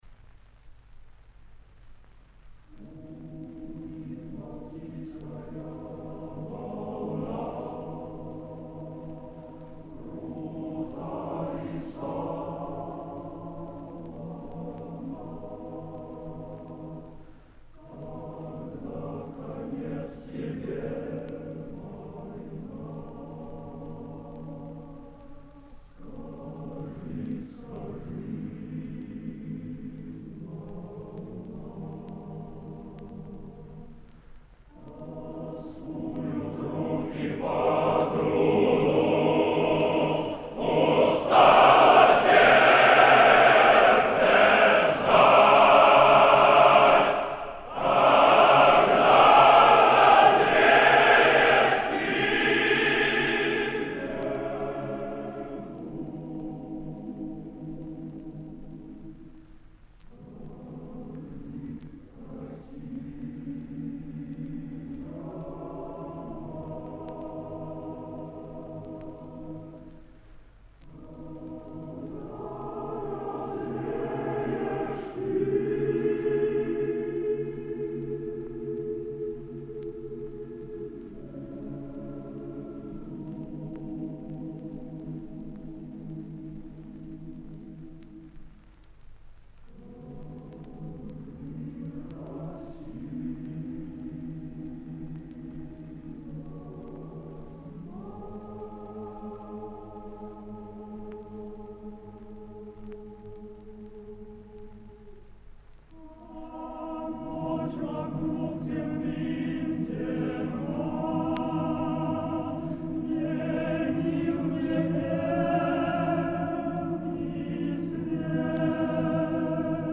Не стал делить на два файла (хоры записаны вместе).